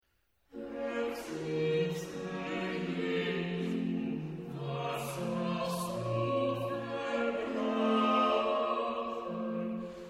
Boven een strikt homofone zetting, onder een gefigureerde versie.
Op de tweede achtstes staan telkens doorgangstonen.
Bach_Choral_Herzliebster_Jesu_Zeile_1.mp3